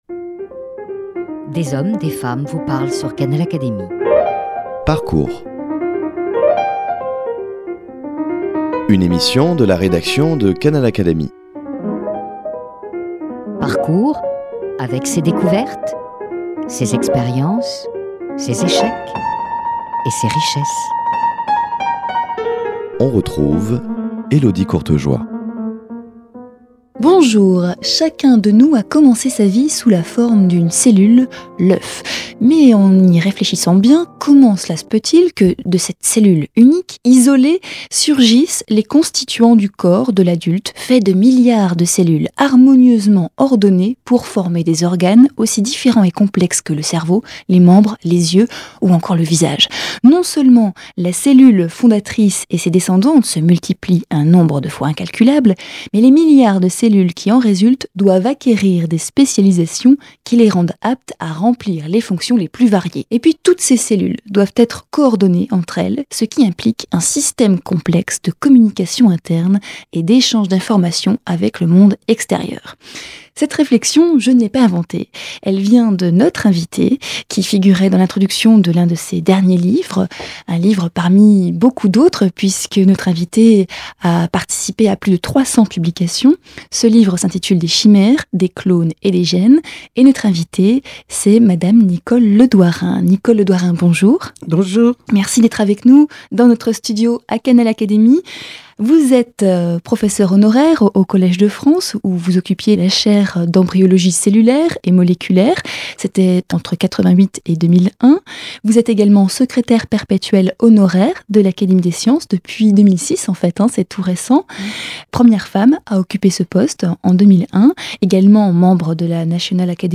Rencontre...
Dans cette émission Parcours , Nicole Le Douarin revient en détail sur ces années de recherche.